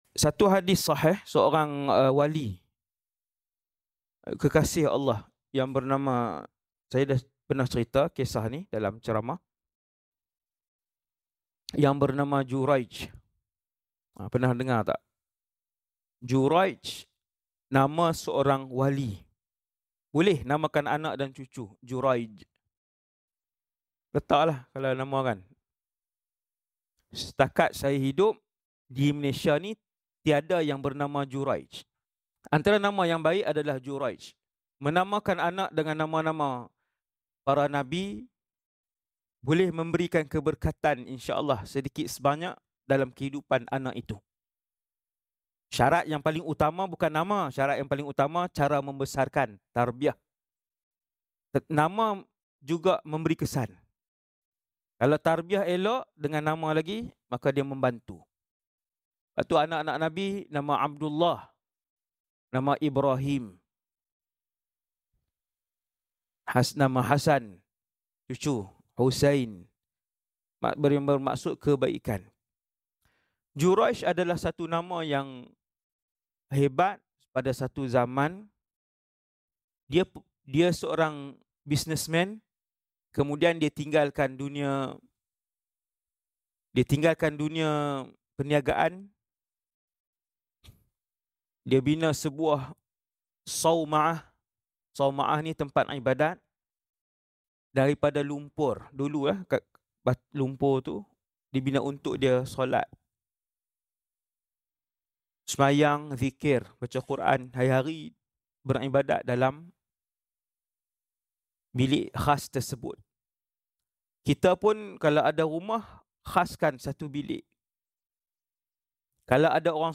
Ceramah Penuh _ Kisah Juraij dan bayi yang bercakap